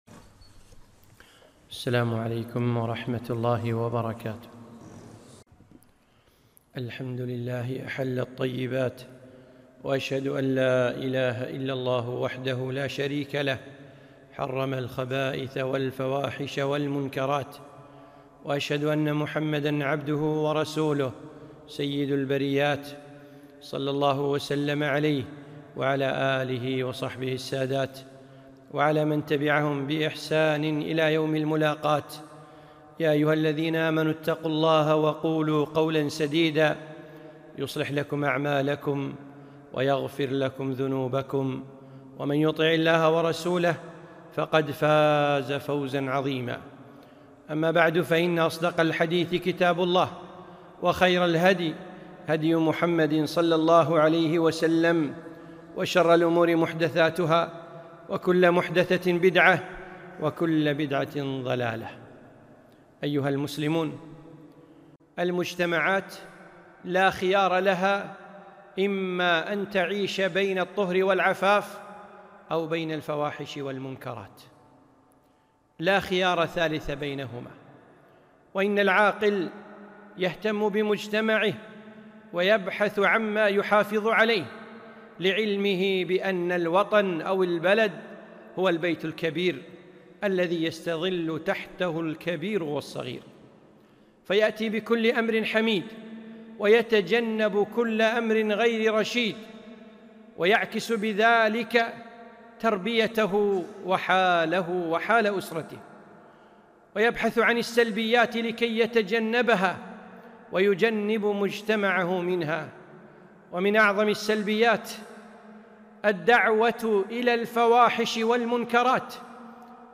خطبة - أثر الفواحش على المجتمع